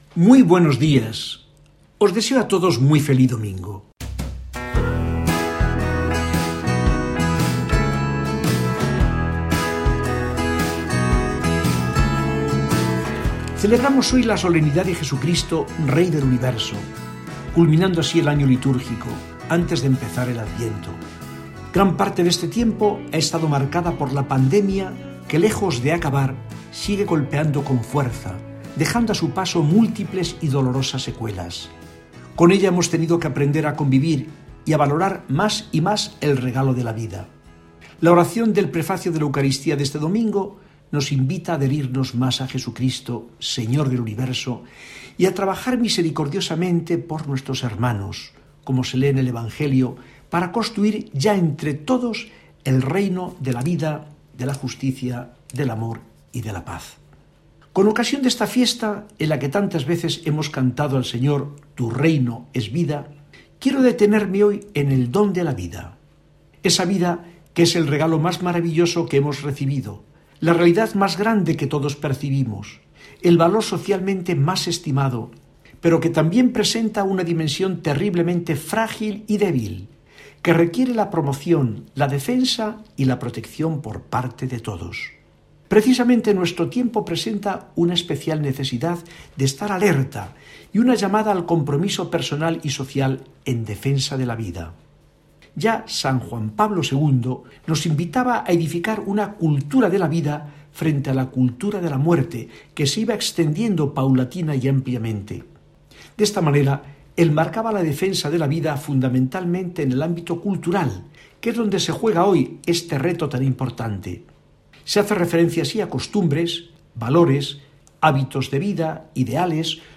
Mensaje del administrador apostólico de la diócesis de Burgos, don Fidel Herráez Vegas, para el domingo 22 noviembre 2020.